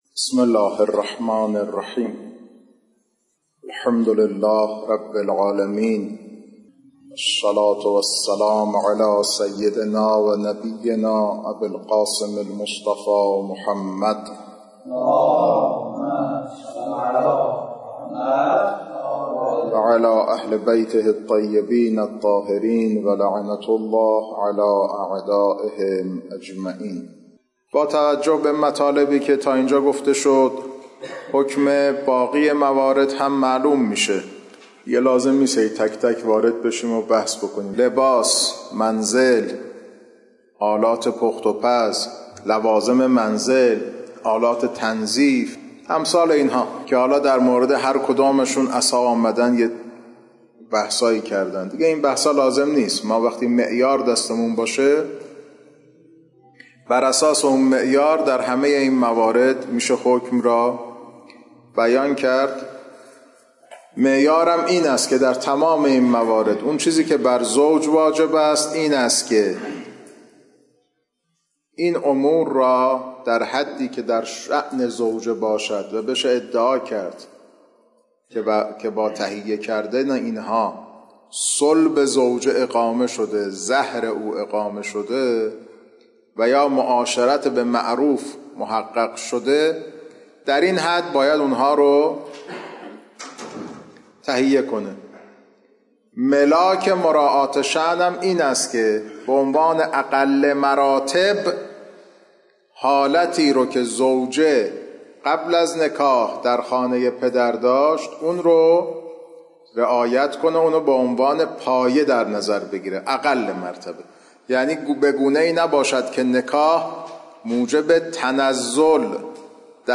خارج فقه